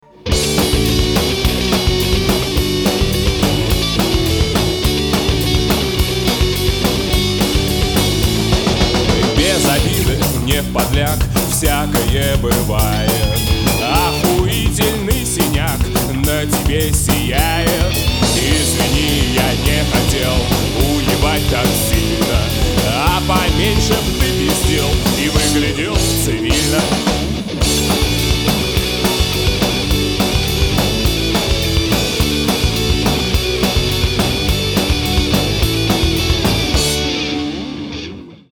песенка (ненормативная лексика)